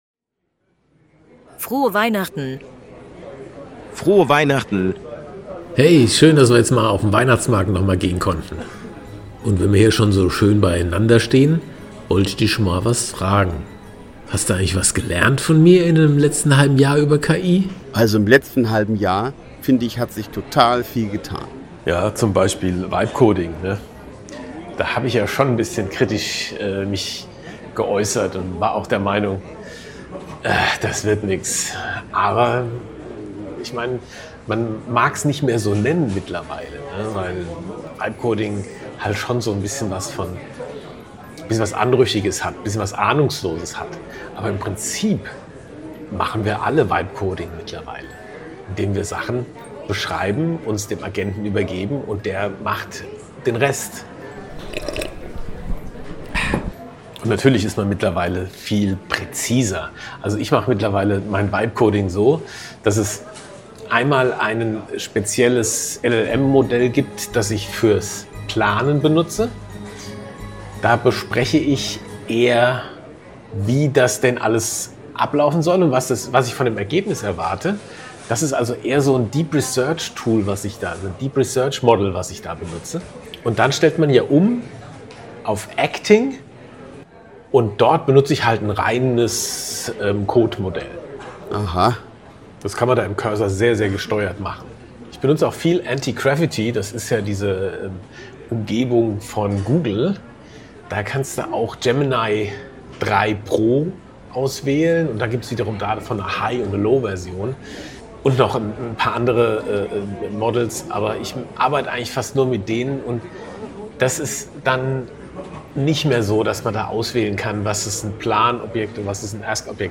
Ein Weihnachtsmarkt-Gespräch über Tool-Hypes, langsame Ernüchterung und warum KI-Projekte ständig neu gedacht werden müssen
Eine persönliche, ruhige Folge zum Jahresende –